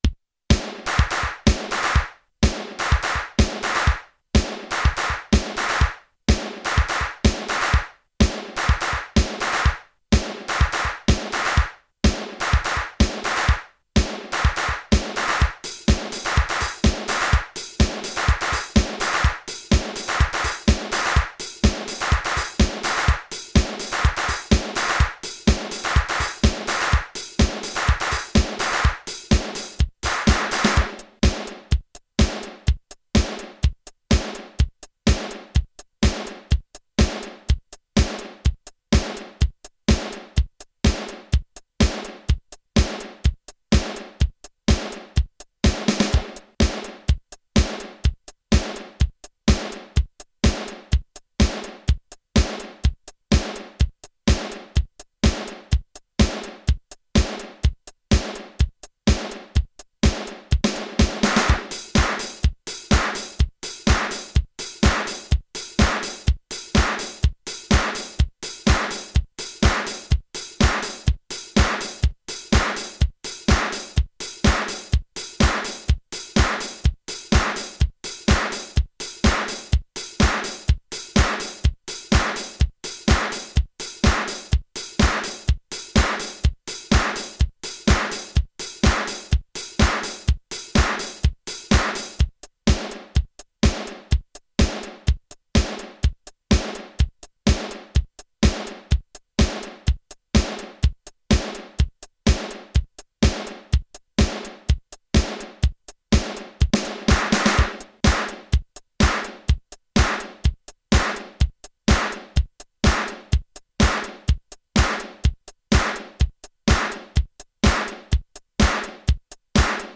2_drum-sequence.mp3